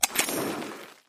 Spin_Button_Click.mp3